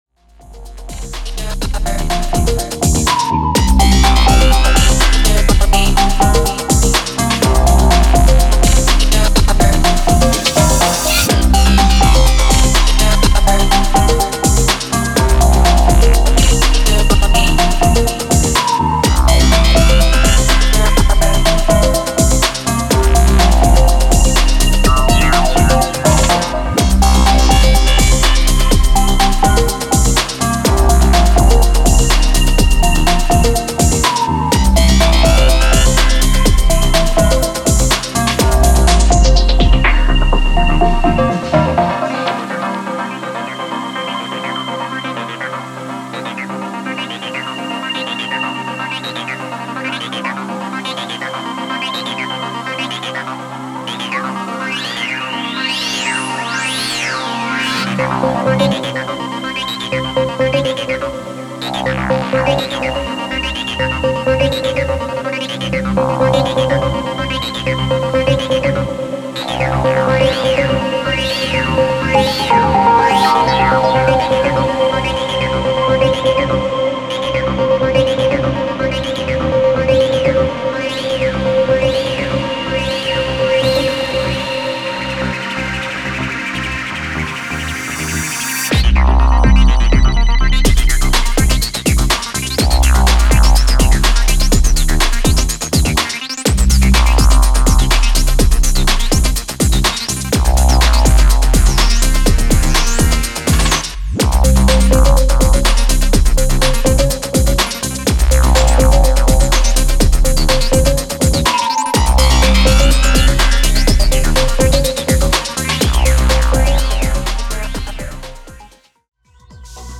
123BPMのブレイクビーツ